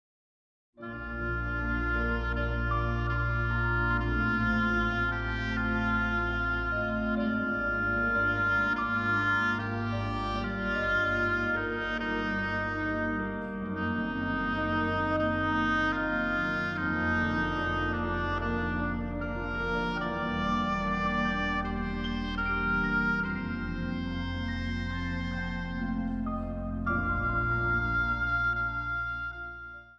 orgel
Hobo
piano/synsthesizer
trompet
trombone.
Zang | Samenzang